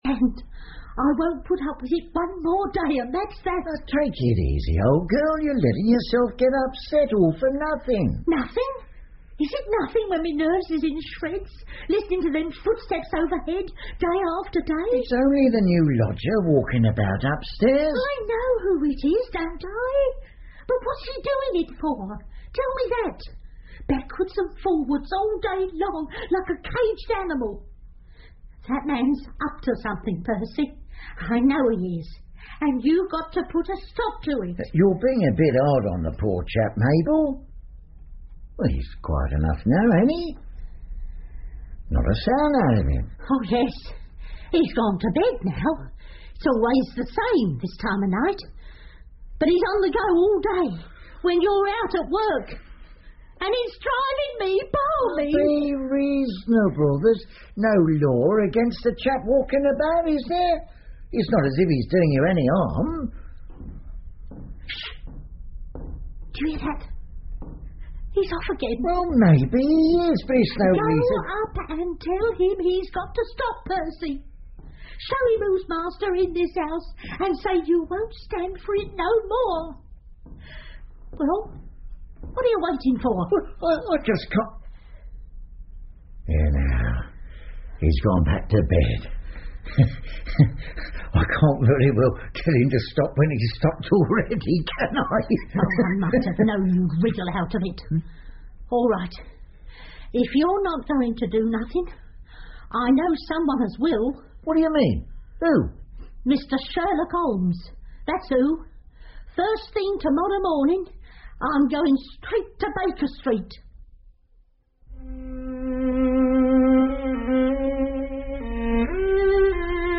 福尔摩斯广播剧 The Red Circle 1 听力文件下载—在线英语听力室